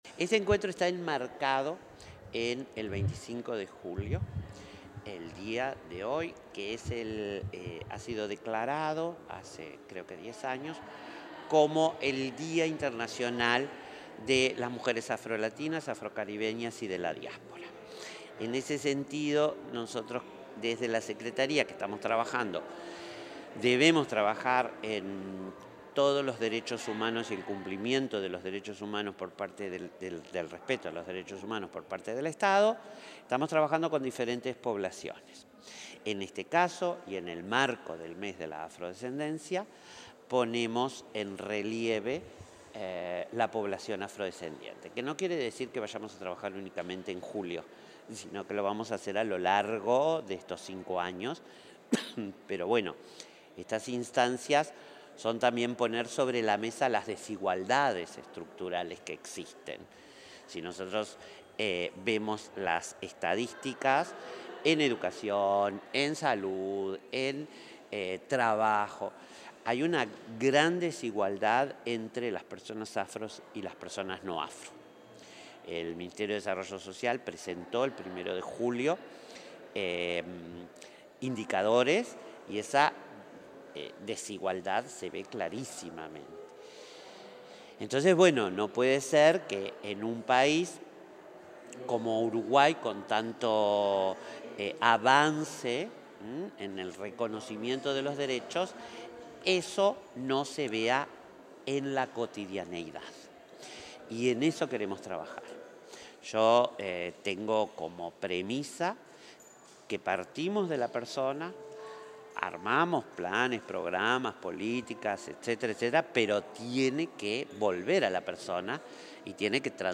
Declaraciones de la titular de la Secretaría de Derechos Humanos de Presidencia
En el marco de la mesa Construyendo un Camino hacia la Igualdad Racial, se expresó la titular de la Secretaría de Derechos Humanos de Presidencia,